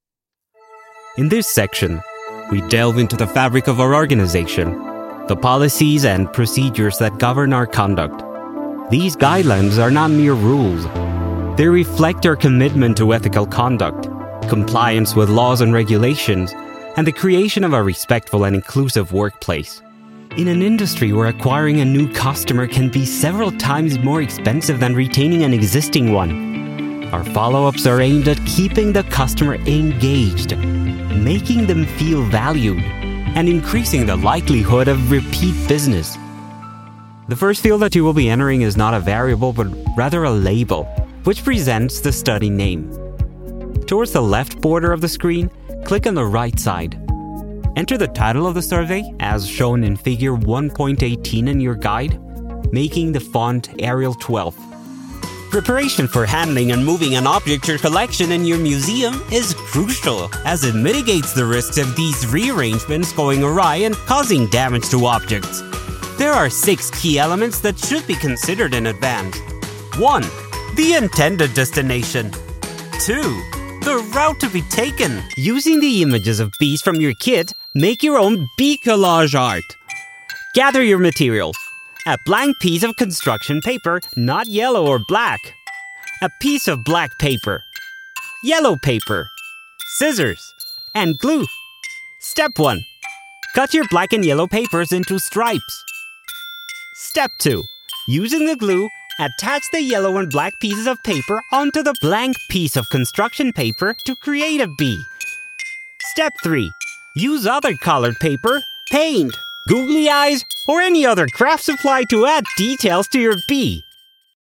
Male
Authoritative, Character, Confident, Friendly, Versatile
Colombian (native), Mexican, Argentinian, Caribbean Spanish
Voice reels
Microphone: Austrian Audio OC18, Shure SM7B, Austrian Audio CC8, t.bone RM 700